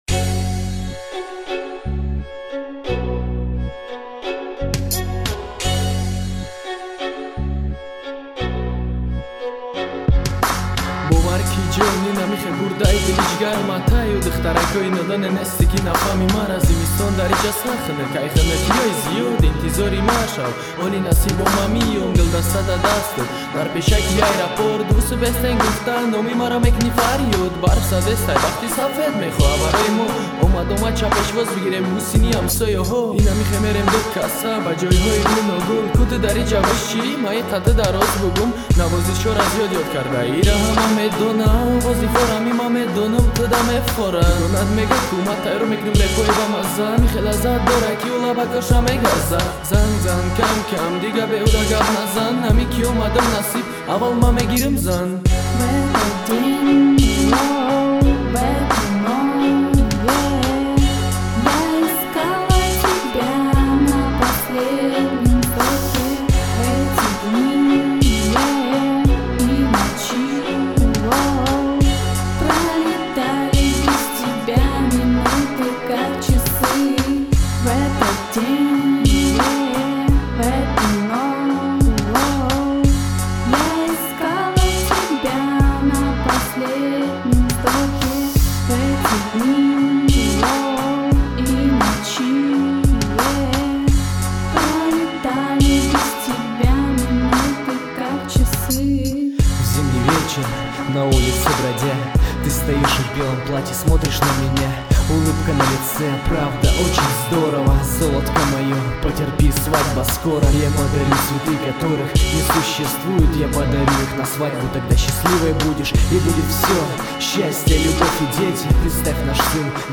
Главная » Файлы » Каталог Таджикских МР3 » Тадж. Rap